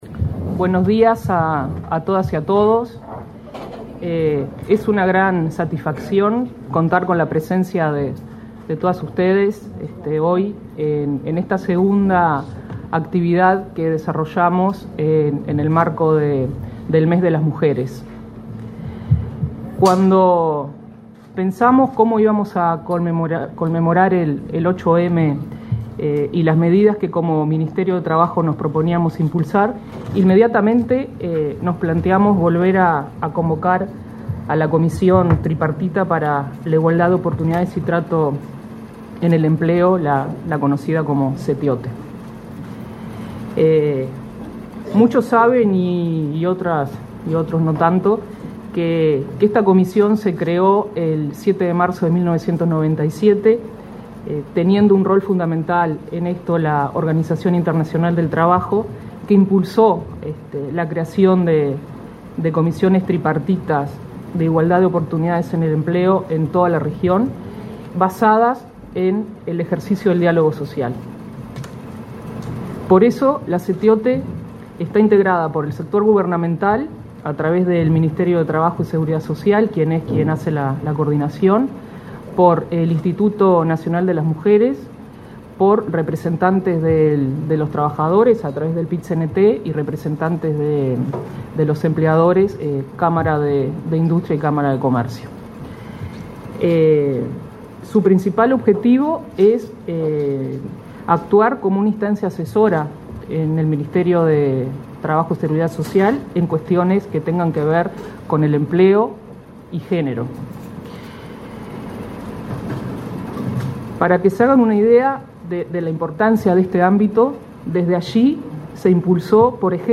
Disertaron en el evento la directora general del MTSS, Laura Bajac, y la directora del Instituto Nacional de las Mujeres, Mónica Xavier.
acto .mp3